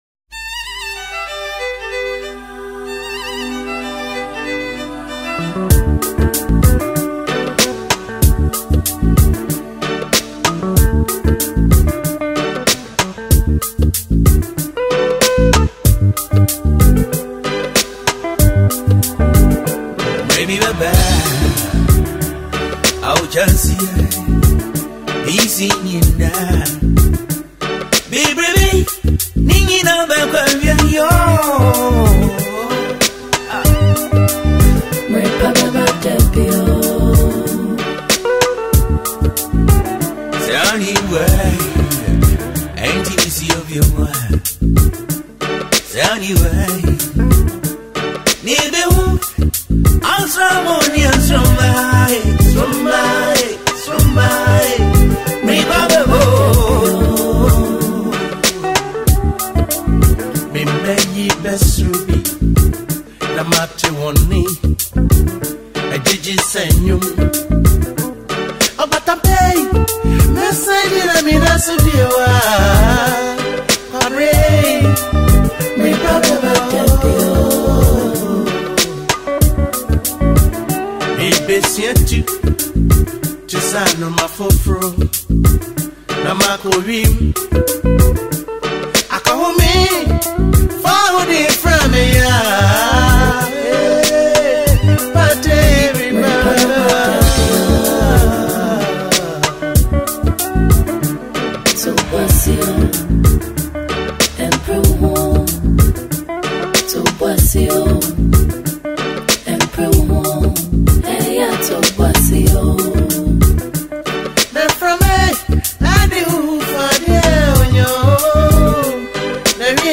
timeless highlife masterpiece
smooth and charismatic vocals
authentic highlife sounds